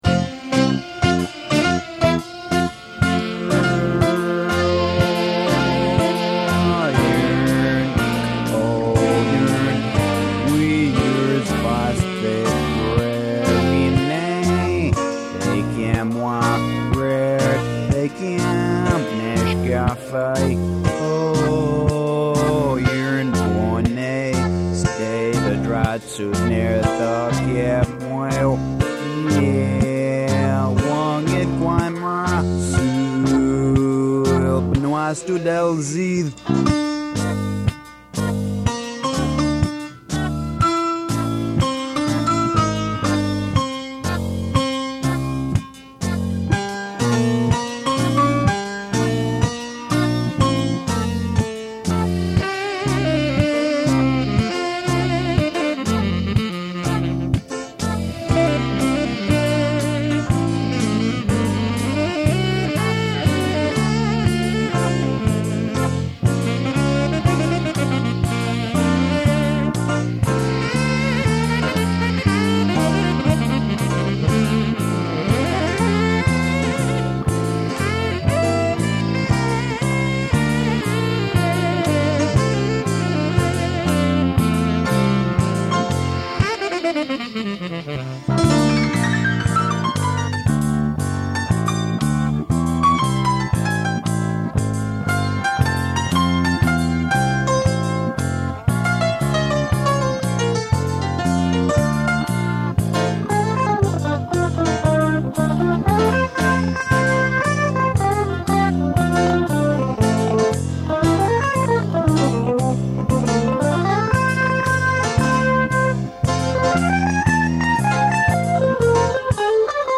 Vocal, electric bass
Tenor sax
Guitar
Acoustic piano
Drums